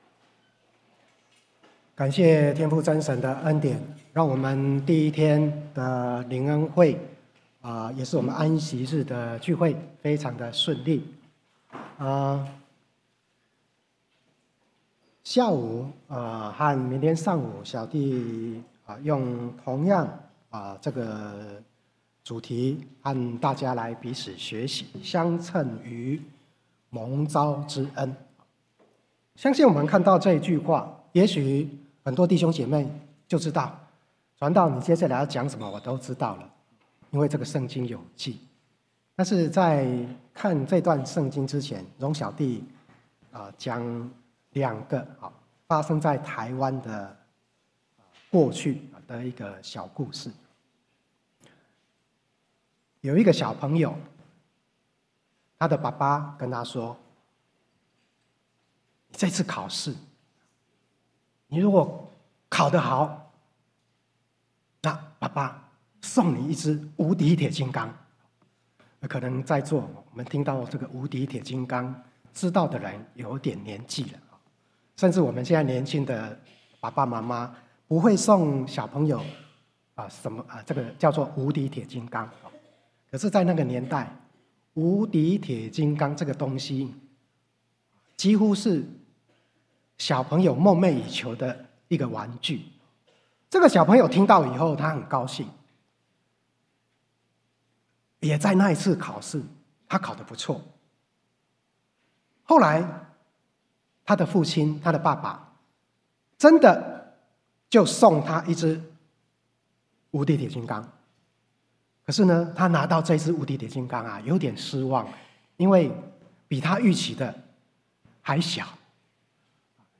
春季靈恩會_相稱於蒙召之恩(上)-講道錄音